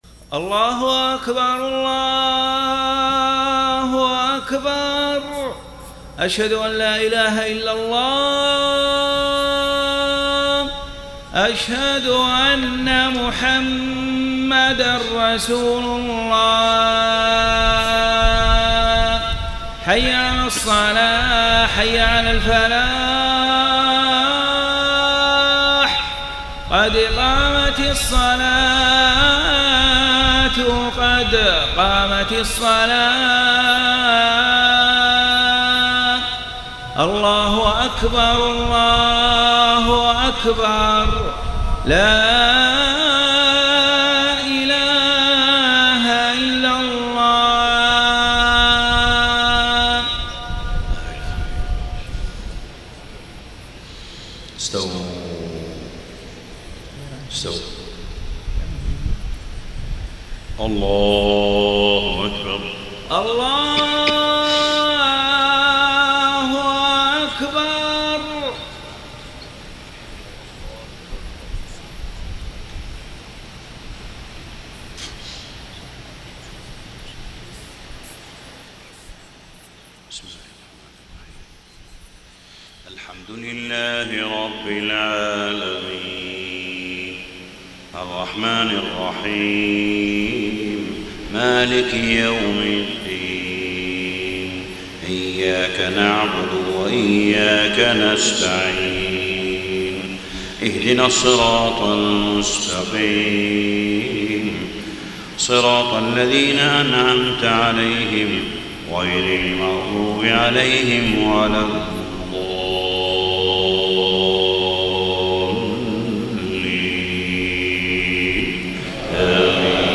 صلاة الفجر 8-9-1434 من سورة النساء > 1434 🕋 > الفروض - تلاوات الحرمين